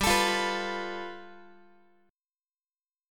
Listen to G9 strummed